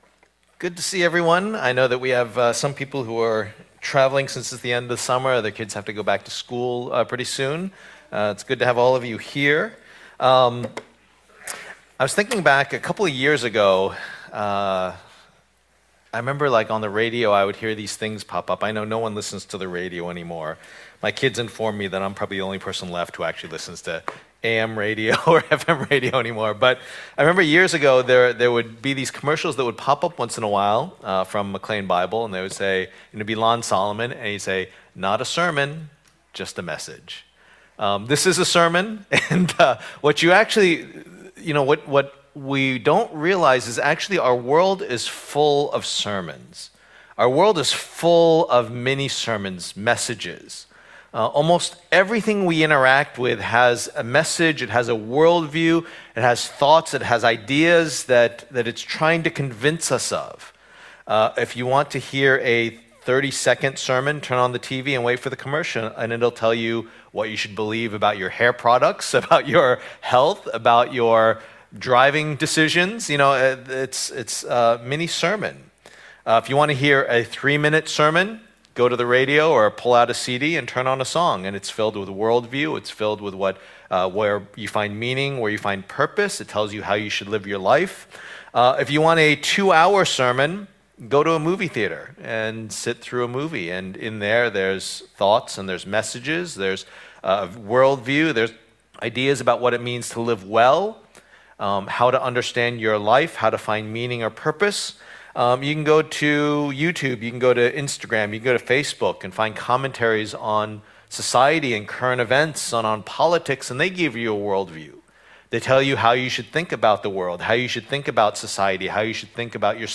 Preacher
Passage: Colossians 2:6-23 Service Type: Lord's Day